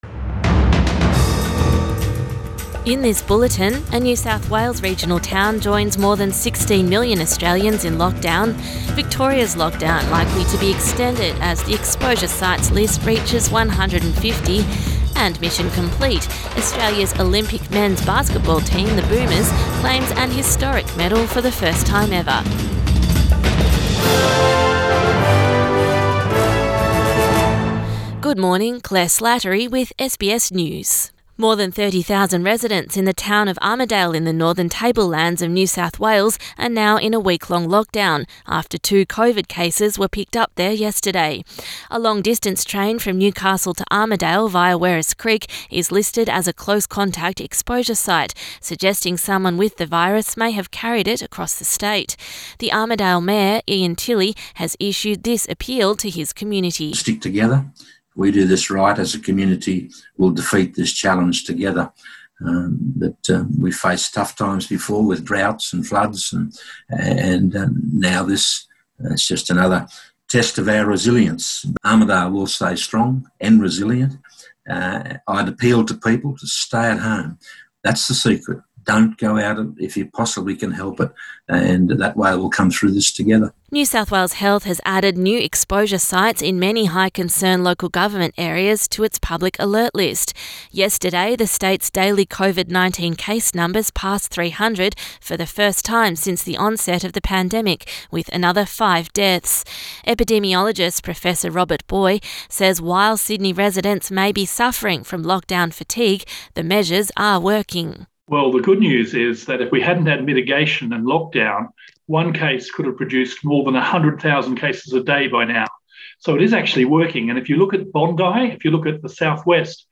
AM bulletin 9 August 2021